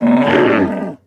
攻击
SFX_LOE_020_Attack.ogg